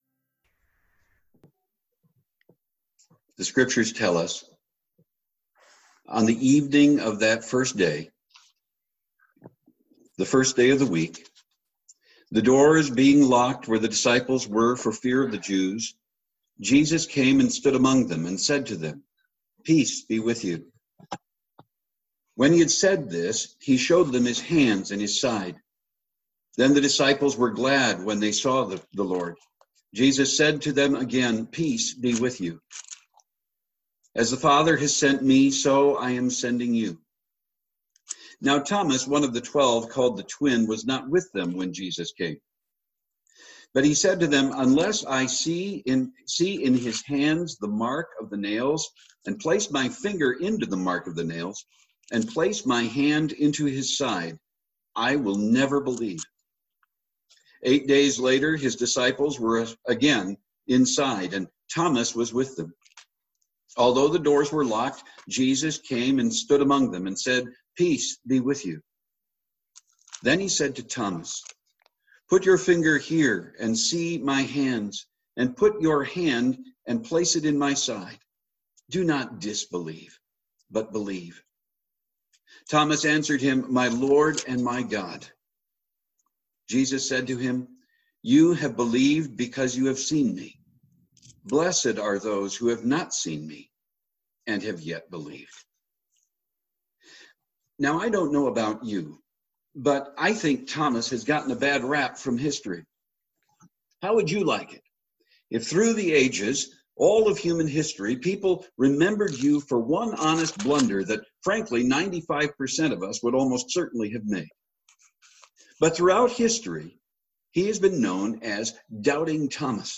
Sermons | The Rock of the C&MA
Easter Sunday 2020